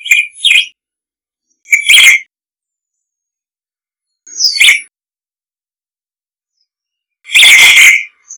Elaenia parvirostris - Fiofió común
fiofiocomun.wav